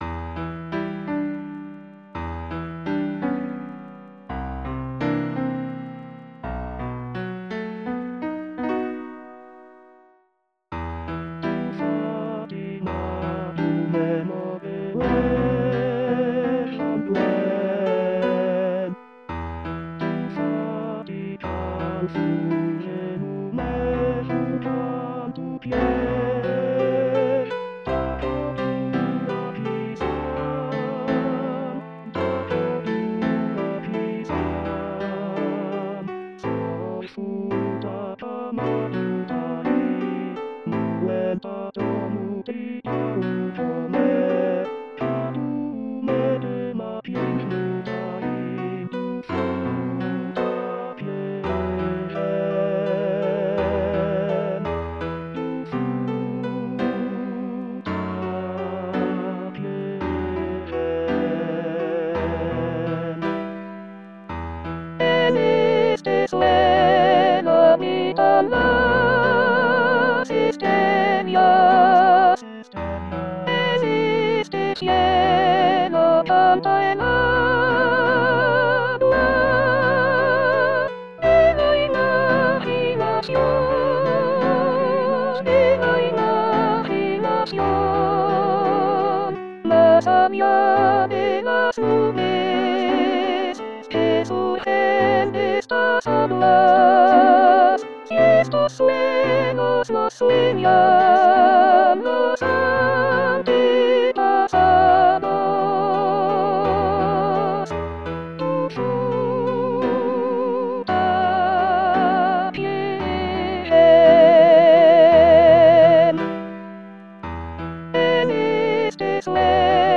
Soprano Soprano 2